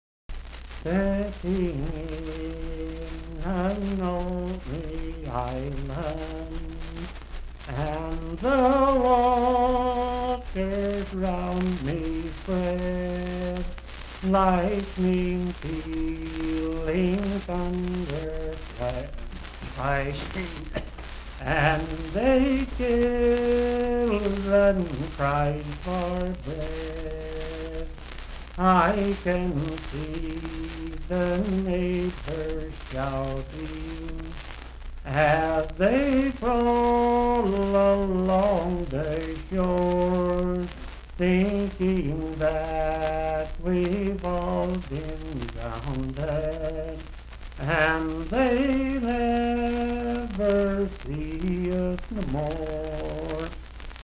Unaccompanied vocal music
Verse-refrain 1d(4).
Voice (sung)